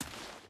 Dirt Walk 5.wav